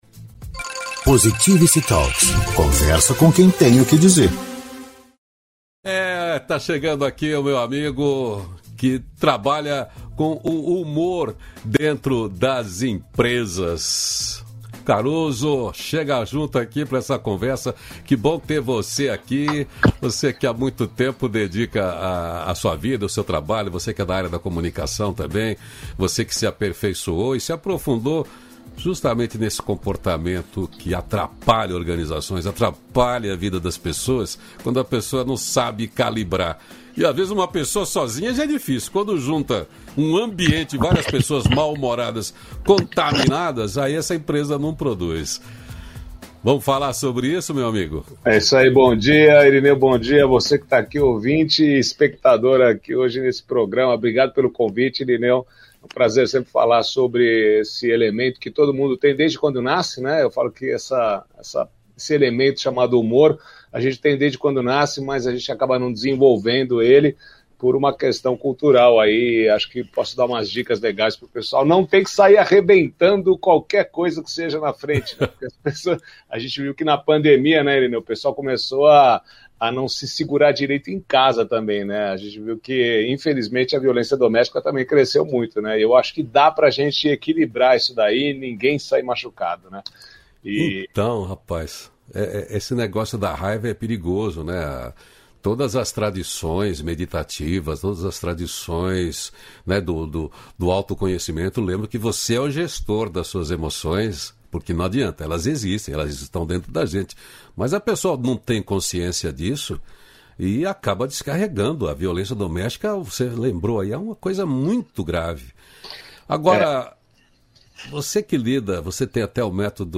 293-feliz-dia-novo-entrevista.mp3